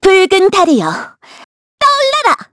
Xerah-Vox_Skill7_kr_Madness.wav